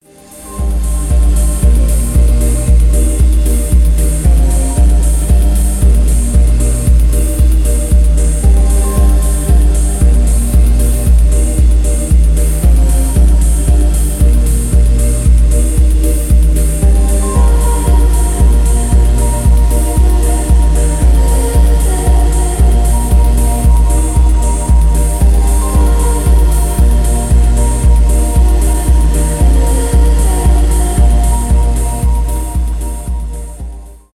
электронные
техно
ambient